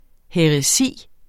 Udtale [ ˌhεʁəˈsiˀ ]